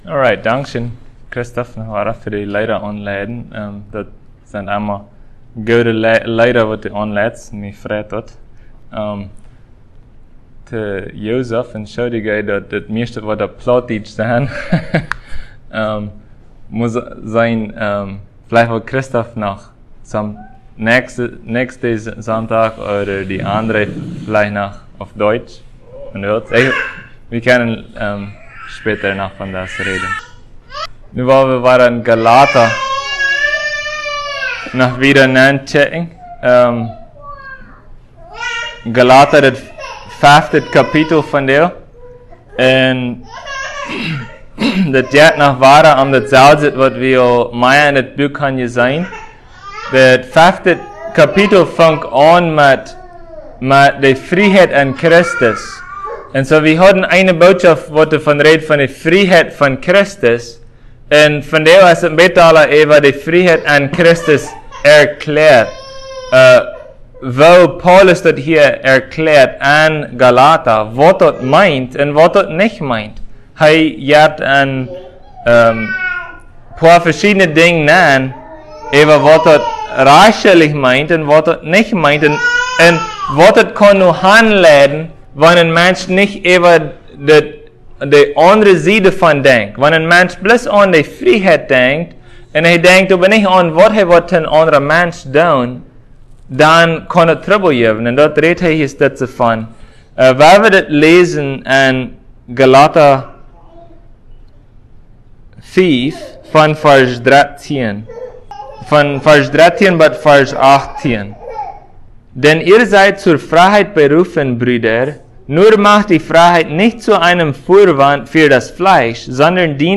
Gal 5:13-18 Service Type: Sunday Plautdietsch « The Fruit of Faith